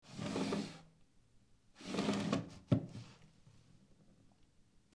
На этой странице собраны уникальные звуки комода: скрипы ящиков, стук дерева, движение механизмов.